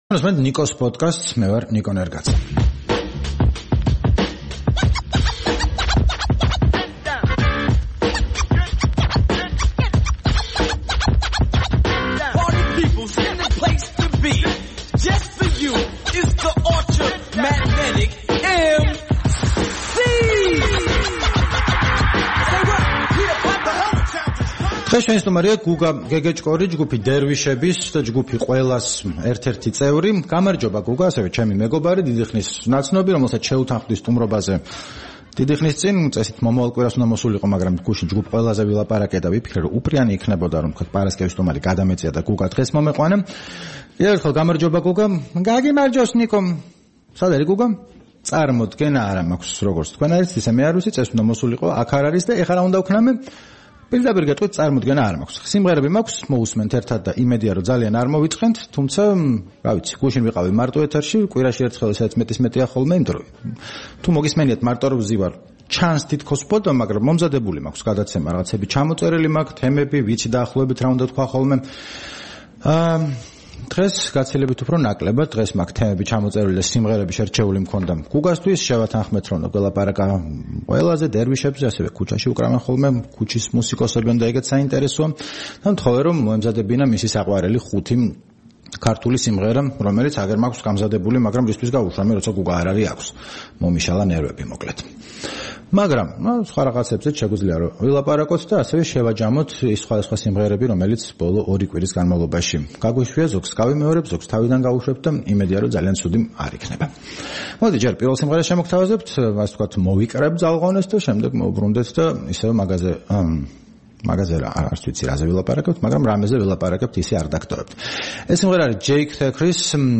კარგ სიმღერებს უშვებს